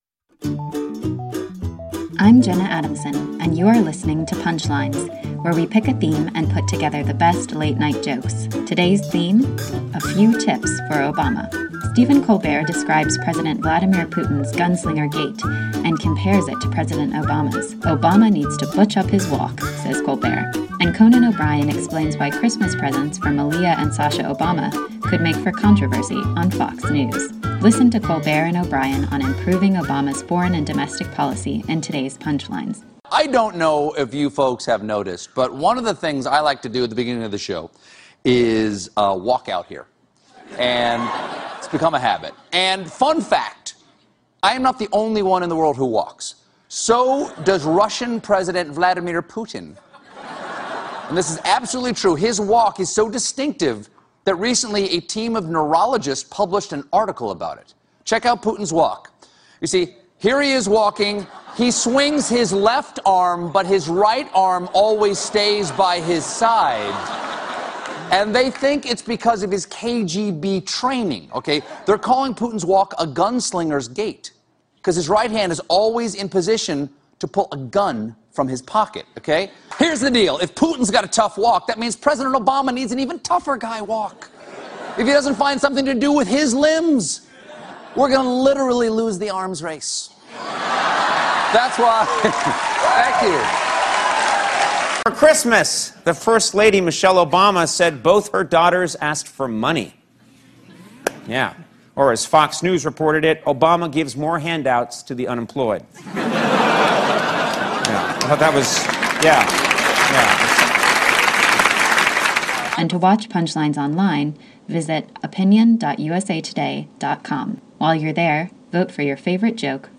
The late-night comics take a look at distinctive walks and presidential holidays.